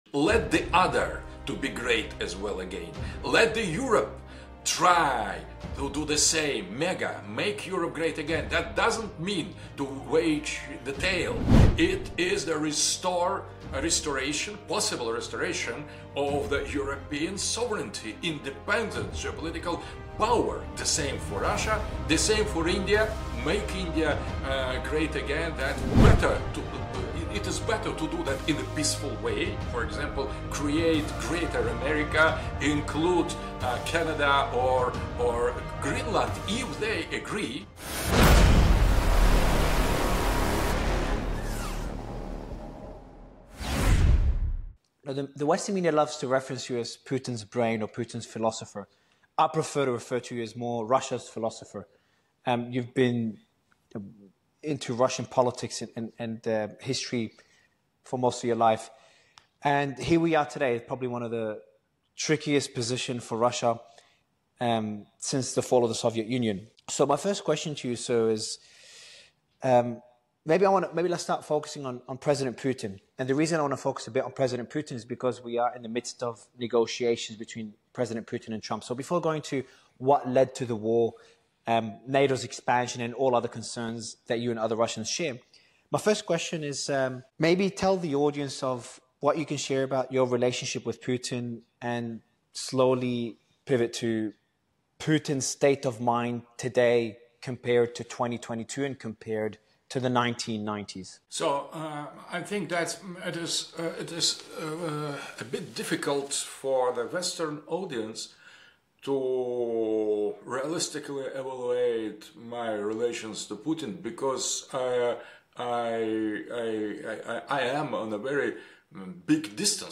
In this exclusive interview, Russian philosopher Alexander Dugin delves into the complexities of Russia's geopolitical landscape, the rise of Trumpism, and the potential for a multipolar world order.